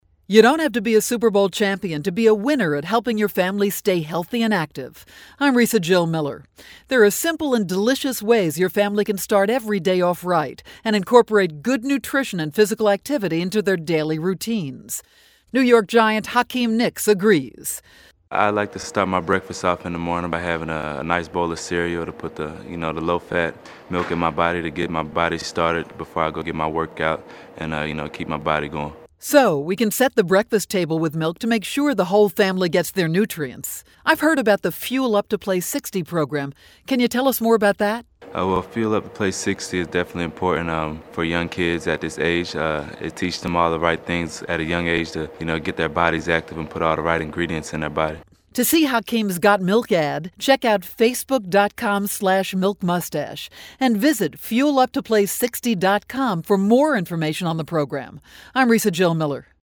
February 13, 2012Posted in: Audio News Release